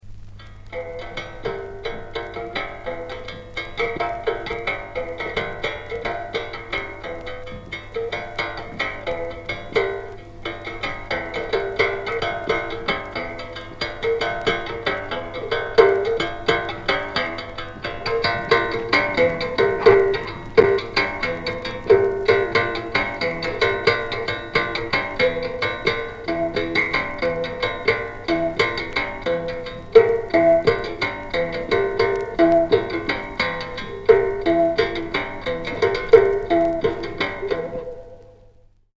アフリカ南部に位置する、英国植民地時代のバストランド(現在のレソト王国)で50年代に録音されたソト族の音楽。
仕事の合間に牧夫が爪弾く原始弓琴の唄うような軋み、口腔を変調器に見立てた弓口琴の滋味深い倍音など、音霊宿る録音の数々。
キーワード：現地録り